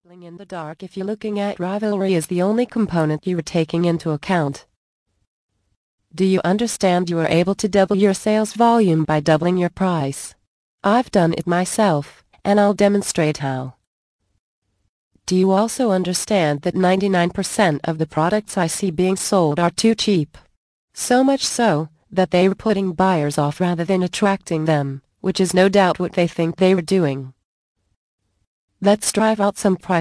Expand Your Sales audio book + FREE Gift